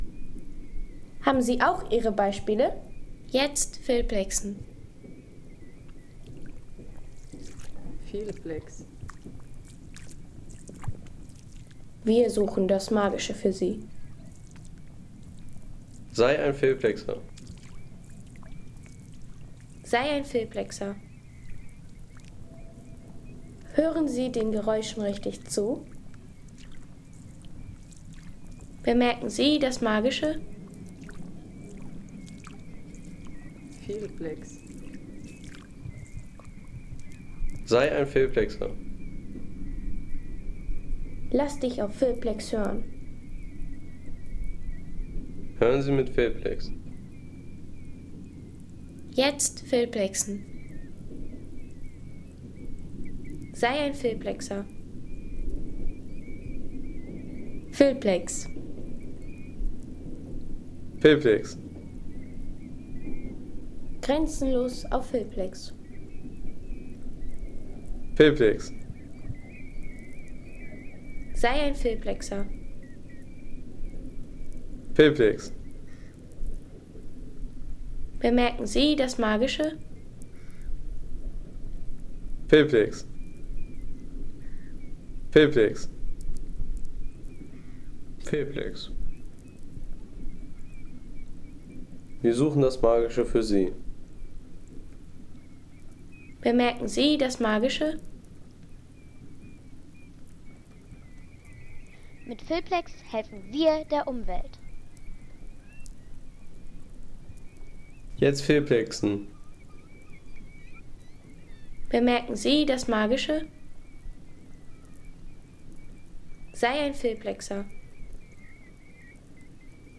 Barfuß-Wanderung im Watt